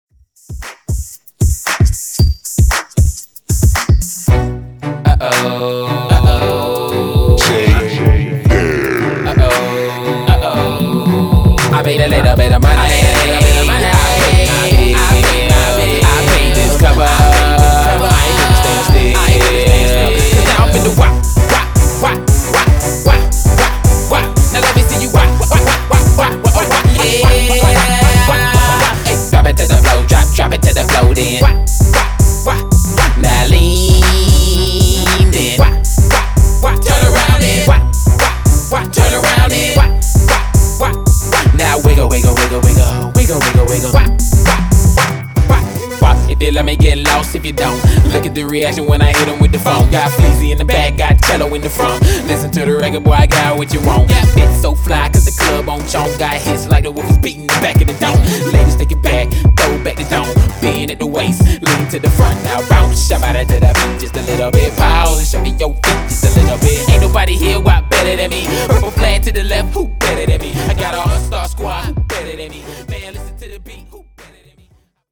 Genres: 70's , 80's , RE-DRUM
Clean BPM: 115 Time